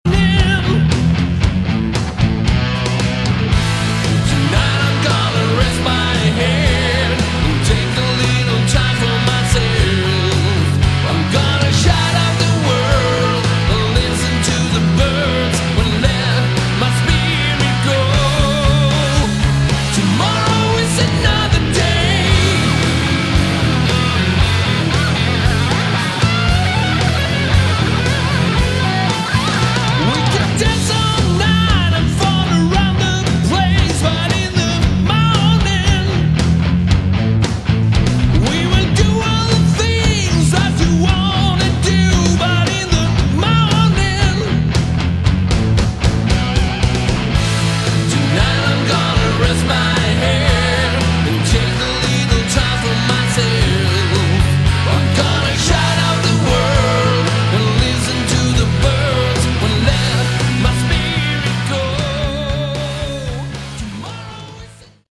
Category: Hard Rock
vocals, guitars
bass